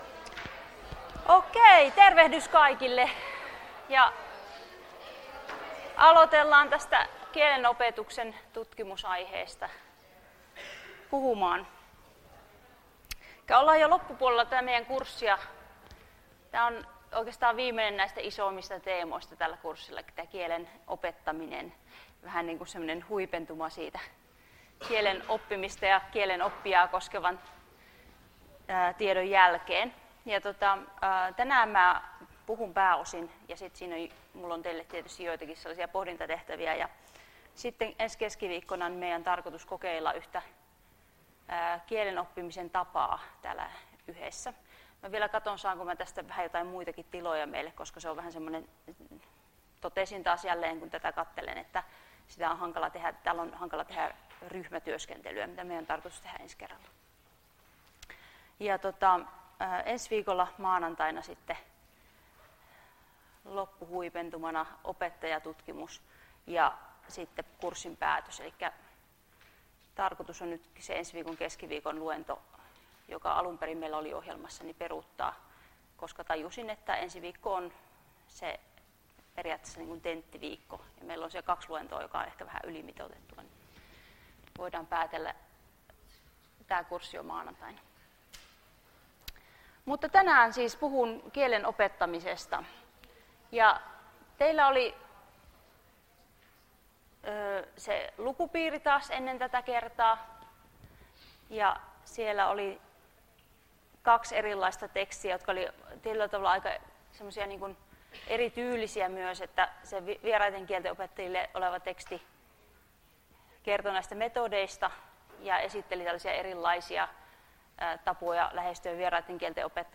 Luento 14.10.2019 — Moniviestin